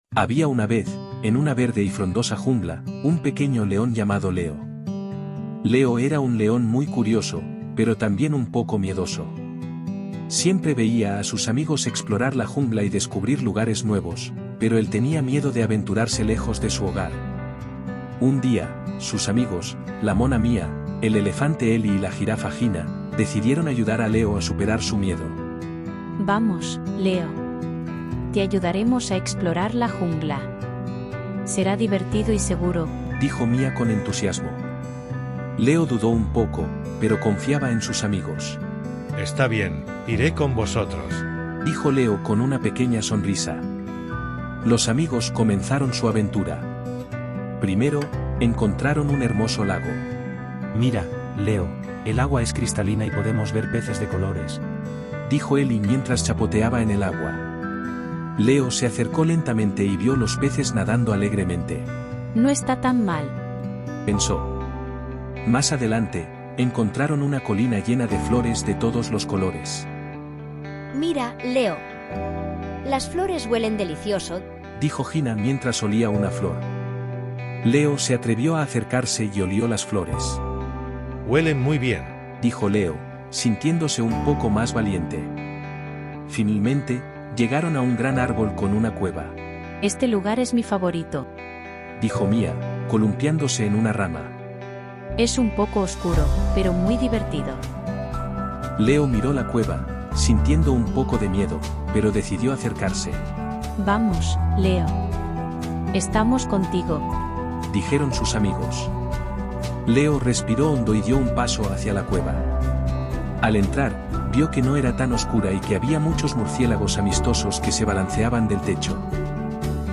3.-Audiolibro-El-leon-Leo-y-la-gran-aventura-en-la-jungla_v2.mp3